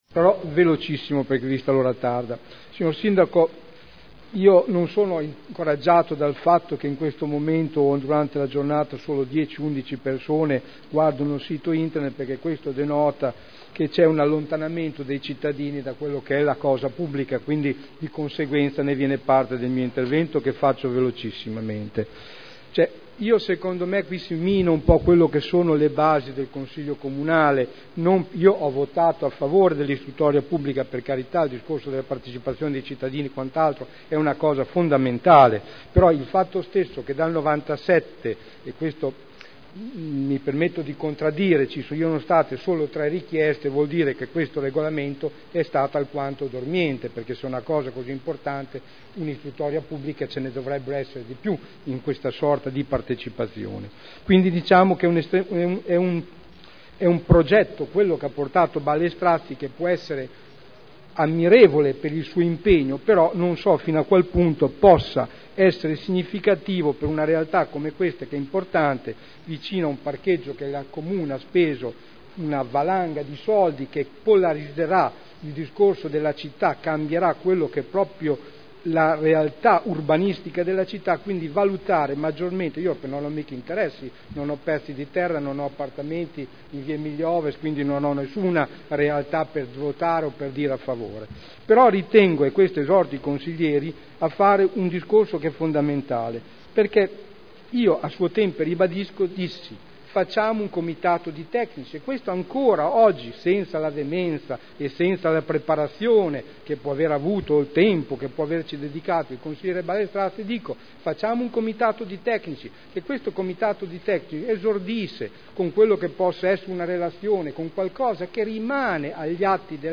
Dichiarazione di voto: Richiesta di istruttoria pubblica ai sensi dell’art. 5 degli istituti di partecipazione del Comune di Modena sul progetto di polo natatorio al Parco Ferrari (Conferenza Capigruppo del 13 settembre 2010)